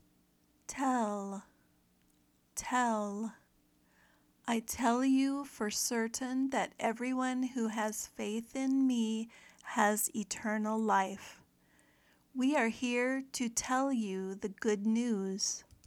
/tel/ (verb)